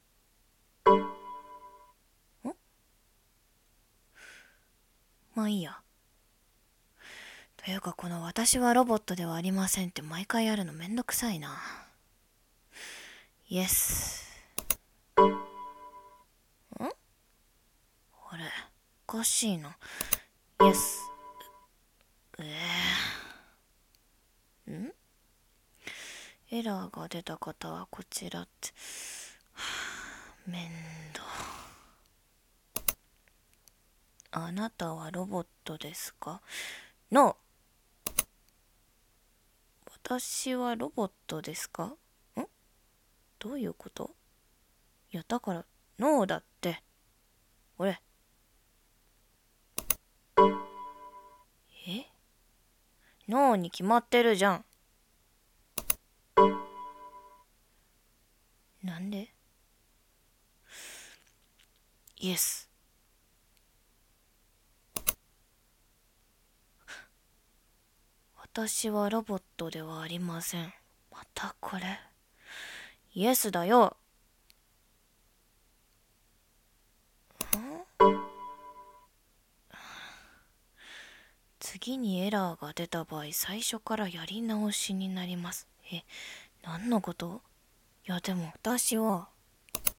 【1人用声劇】